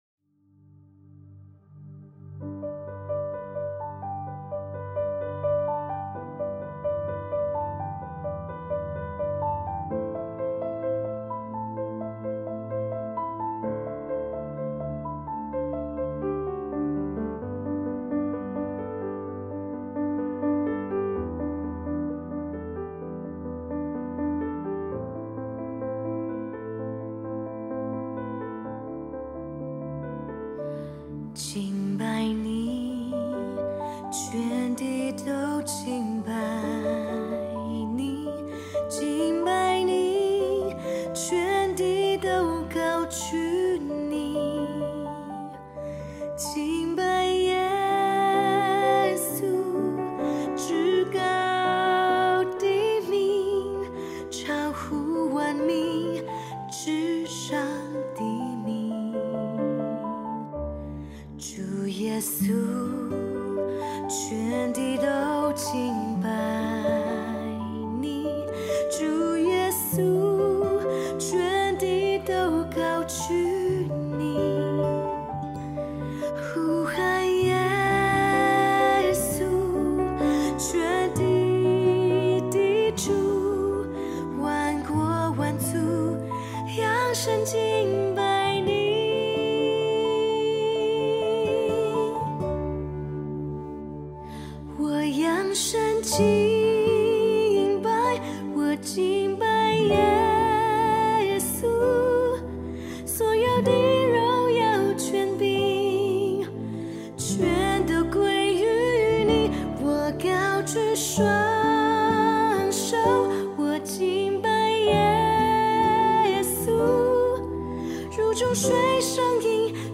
2024-12-15 敬拜诗歌 | 预告